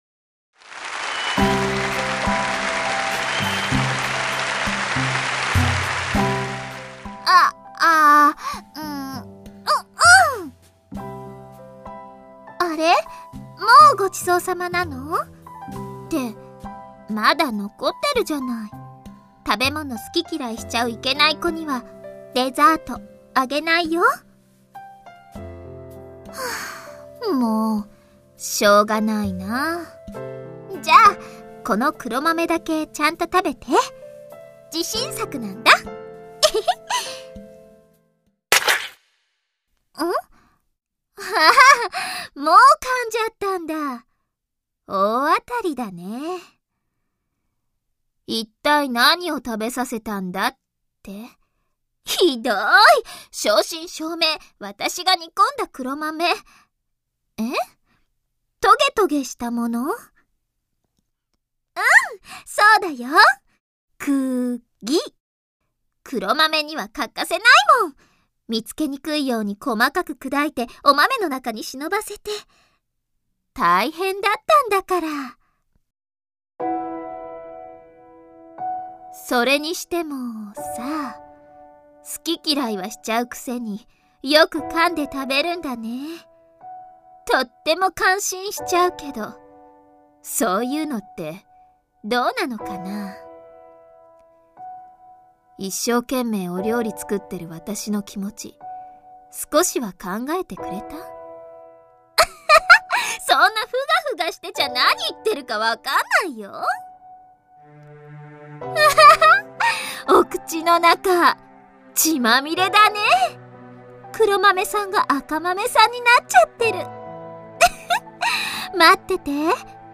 WEBドラマ 第4回を公開！！